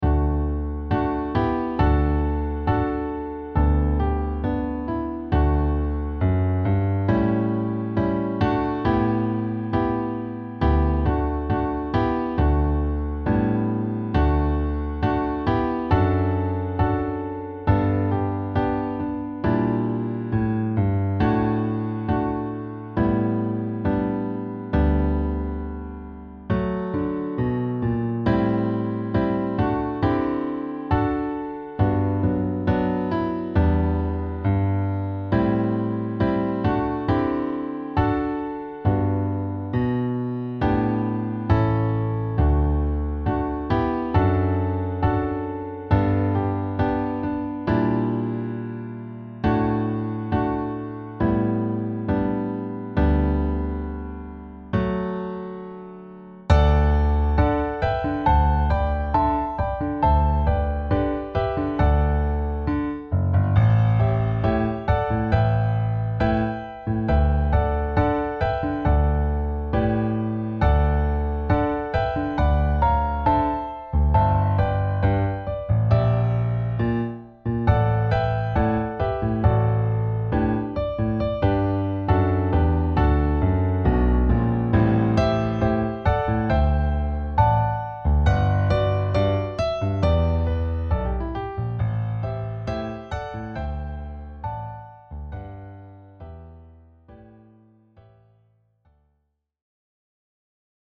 Instrumentierung: Klavier solo